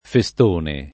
[ fe S t 1 ne ]